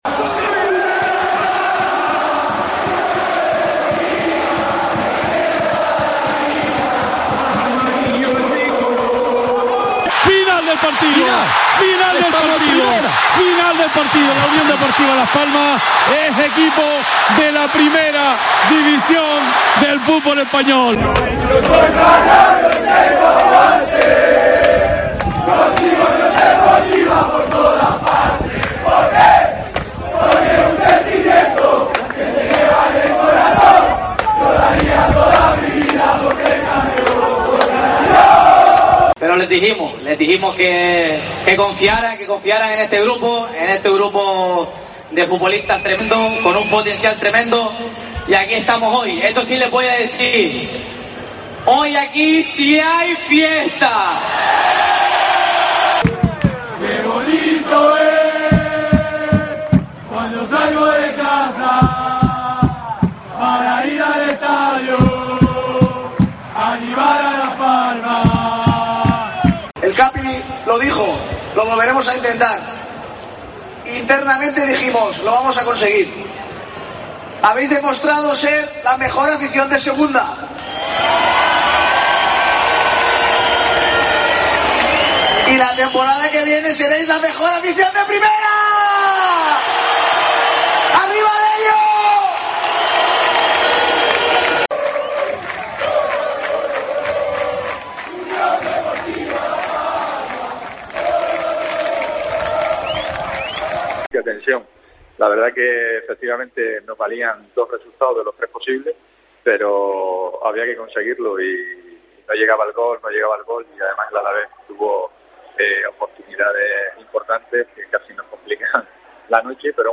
Sonidos del ascenso de la UD Las Palmas